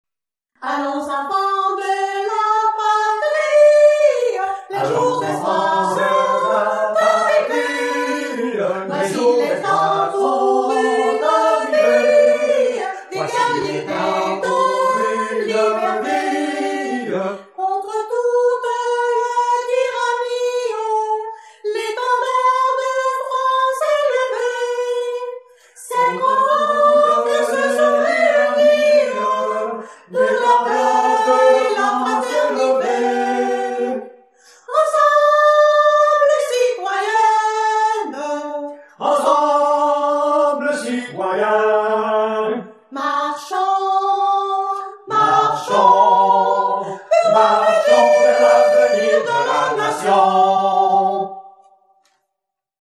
Canon à 2 voix
La Citoyenne, duo, voix humaines